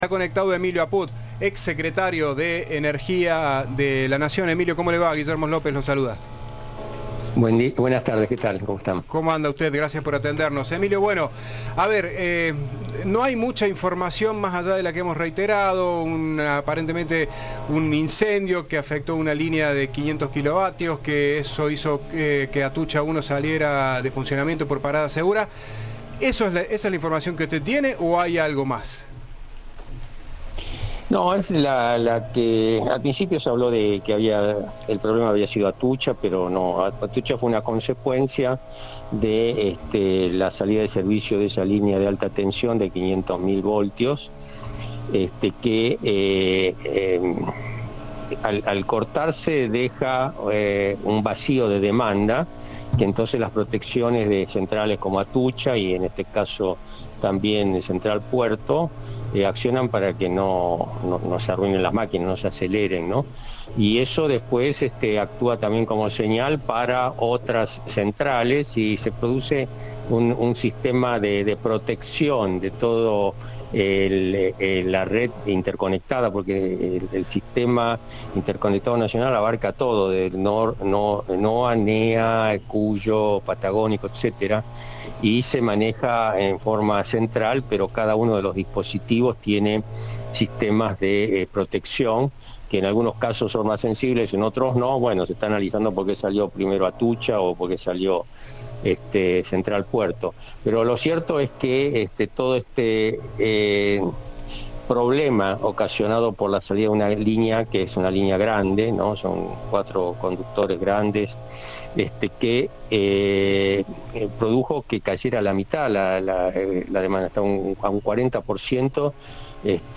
Emilio Apud, exsecretario de Energía y Minería de la Nación, expresó, en diálogo con Cadena 3, la problemática por la desinversión que se vive y el bajo precio que se paga hoy en las tarifas con subsidios estatales que no cubren los costos necesarios.
Entrevista de "Informados, al regreso".